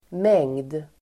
Uttal: [meng:d]